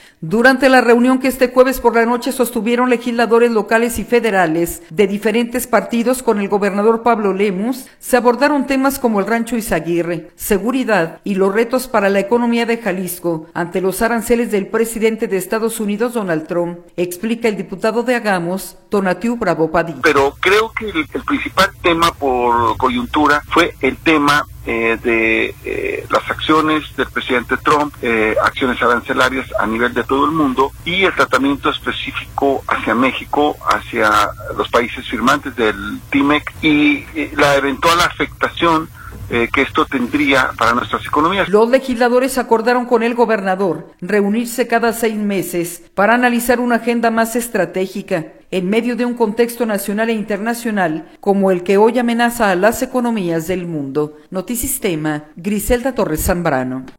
audio Durante la reunión que este jueves por la noche sostuvieron legisladores locales y federales de diferentes partidos con el gobernador Pablo Lemus, se abordaron temas como el Rancho Izaguirre, seguridad y los retos para la economía de Jalisco, ante los aranceles del presidente de Estados Unidos Donald Trump, explica el diputado de Hagamos, Tonatiuh Bravo Padilla.